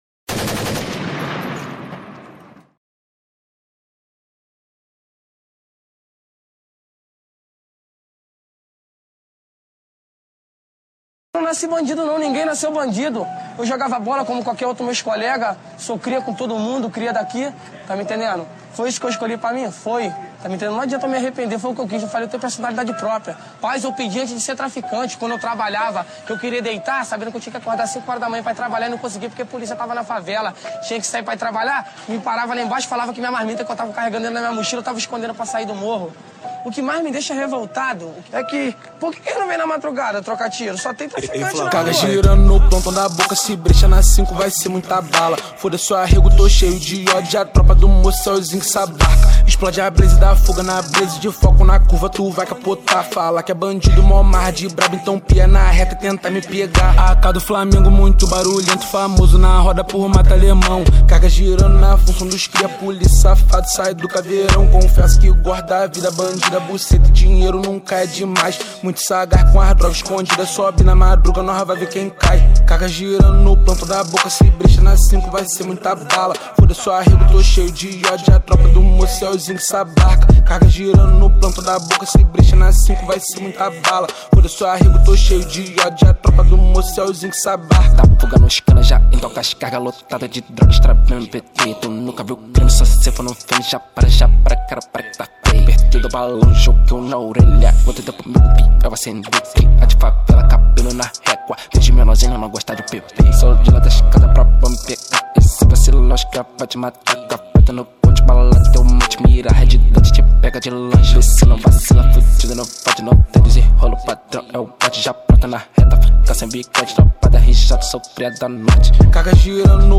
2024-02-20 11:16:29 Gênero: Trap Views